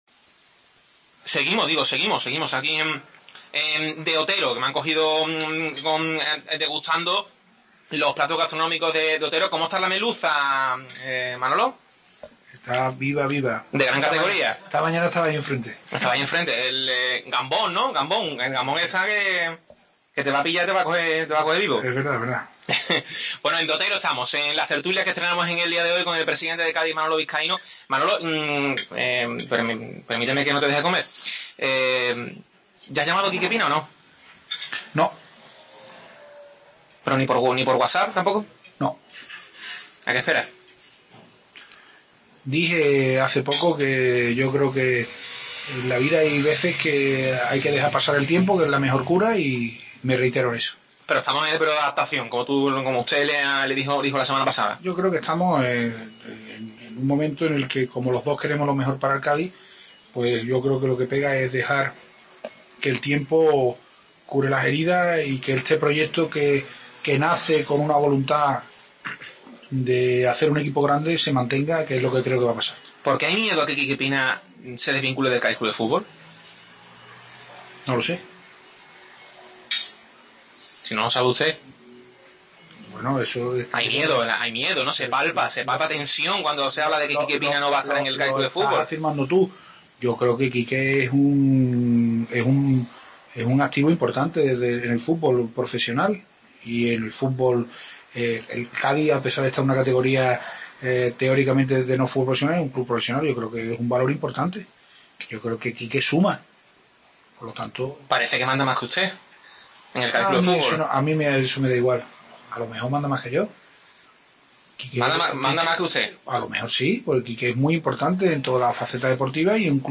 Segunda parte de la tertulia